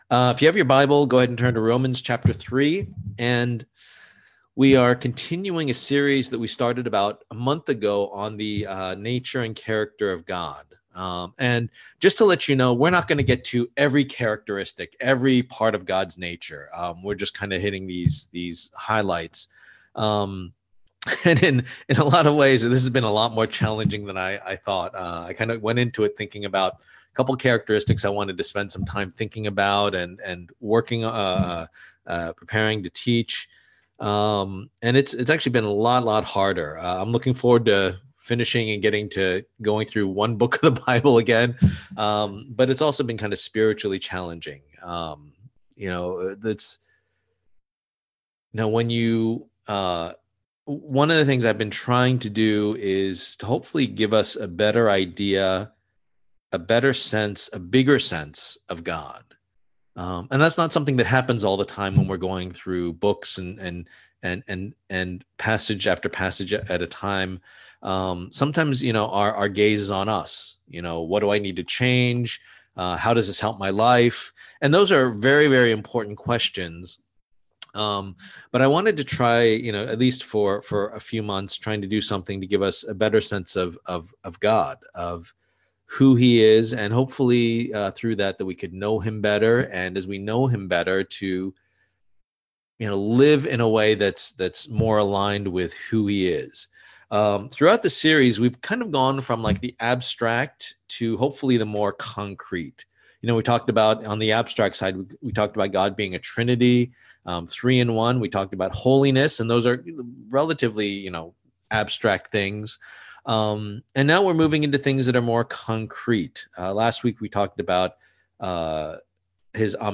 Passage: Romans 3:9-26 Service Type: Lord's Day